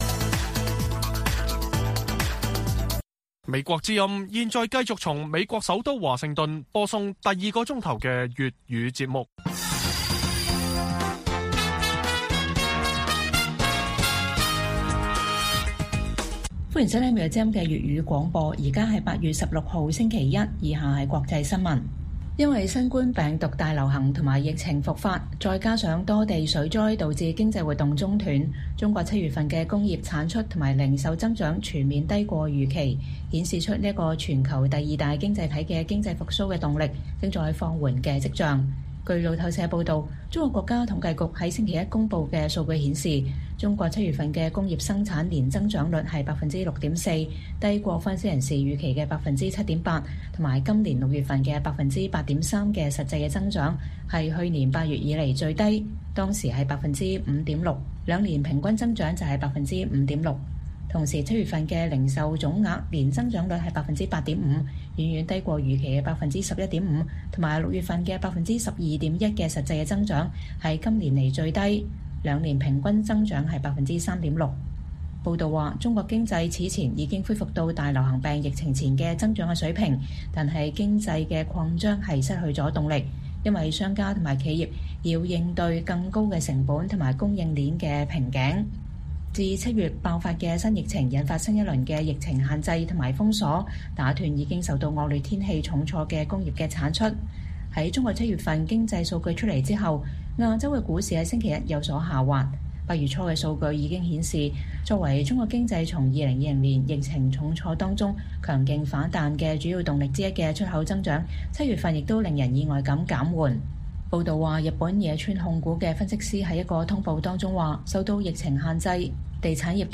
粵語新聞 晚上10-11點: 中國七月經濟復甦動力受疫情水災影響放緩